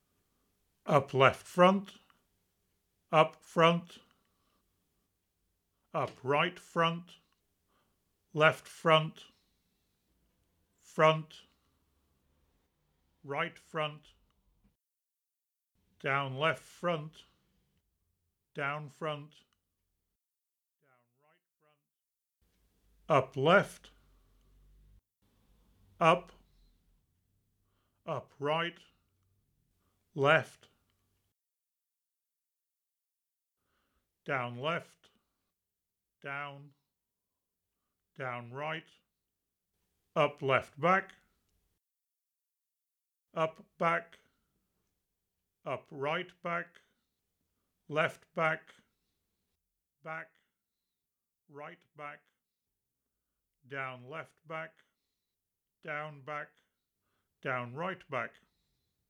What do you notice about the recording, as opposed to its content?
surround_test_ambiX.wav